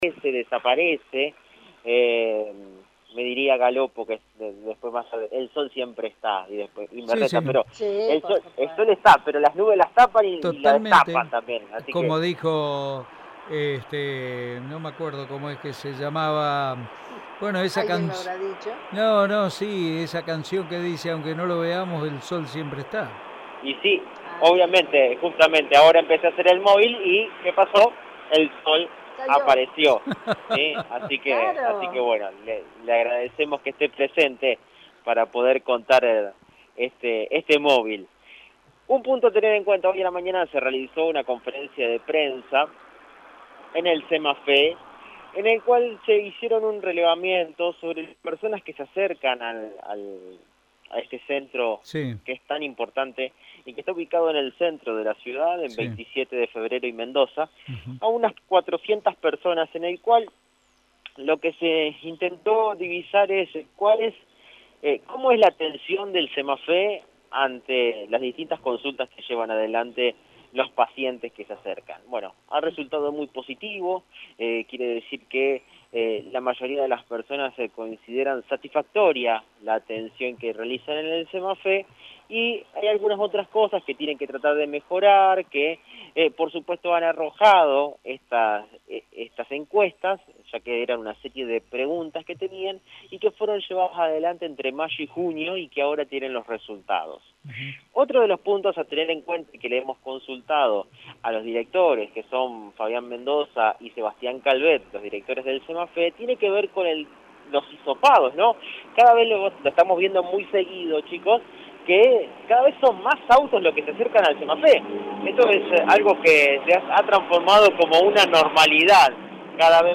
AUDIO DESTACADOSanta Fe